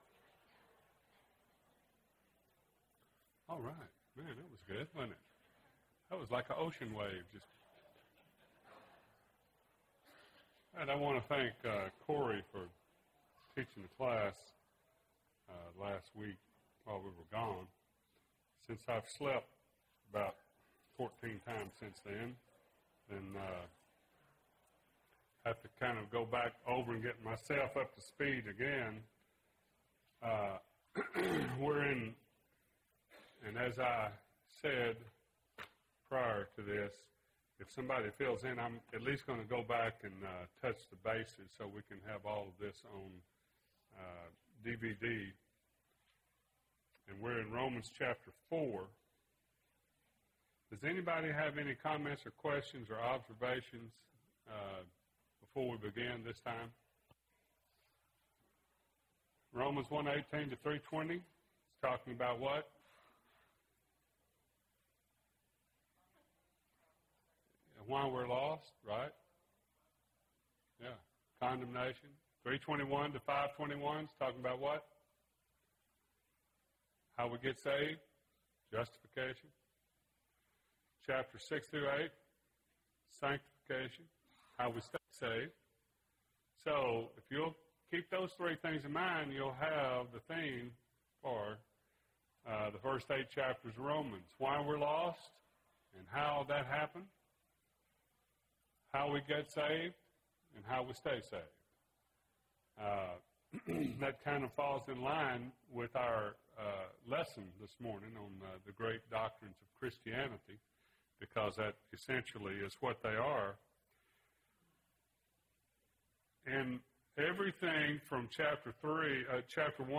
Romans – Blessed In Justification and Sanctification (11 of 24) – Bible Lesson Recording
Sunday AM Bible Class